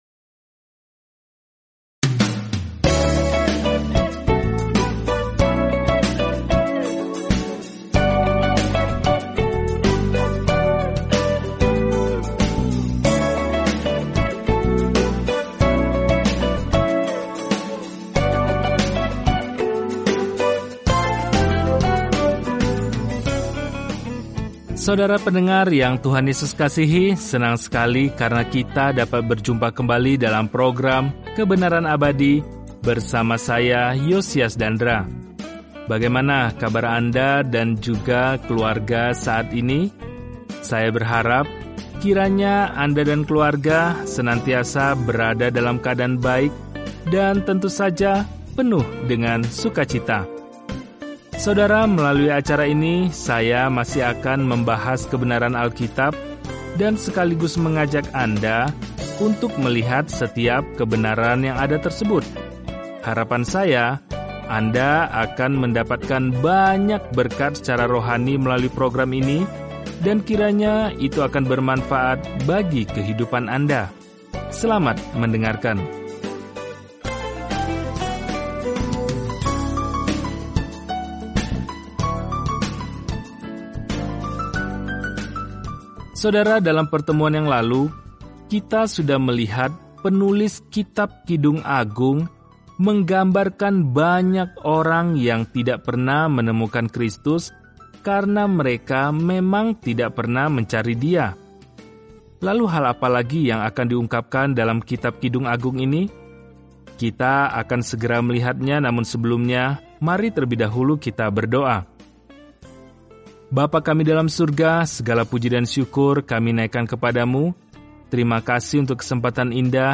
Firman Tuhan, Alkitab Kidung Agung 2:16-17 Kidung Agung 3 Hari 9 Mulai Rencana ini Hari 11 Tentang Rencana ini Kidung Agung adalah lagu cinta kecil yang merayakan cinta, hasrat, dan pernikahan dengan perbandingan luas dengan bagaimana Tuhan pertama kali mencintai kita. Perjalanan sehari-hari melalui Kidung Agung sambil mendengarkan studi audio dan membaca ayat-ayat tertentu dari firman Tuhan.